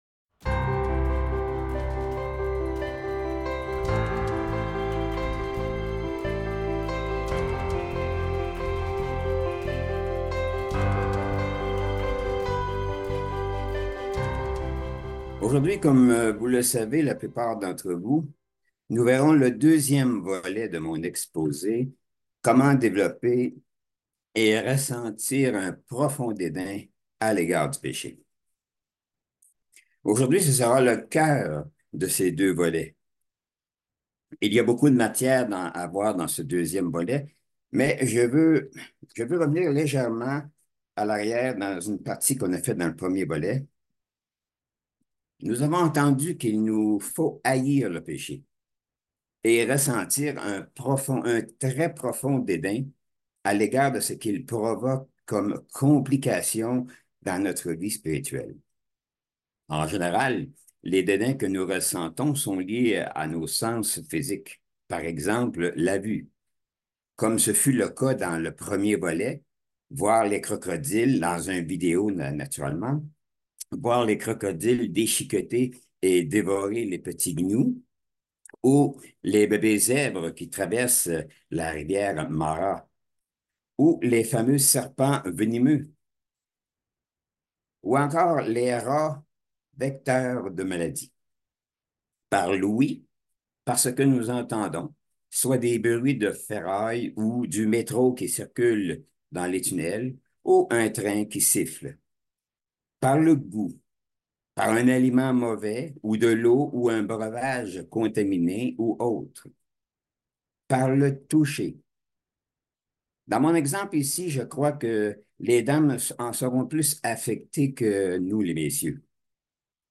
Ce sermon explore l'importance de développer un profond dédain pour le péché afin de renforcer la croissance spirituelle. L'orateur utilise des analogies de la nature pour illustrer la lutte entre le bien et le mal, soulignant la nécessité d'être vigilants et de rejeter activement le péché. Il encourage l'utilisation de la prière et de l'étude de la Bible pour aider dans ce processus.
Given in Bordeaux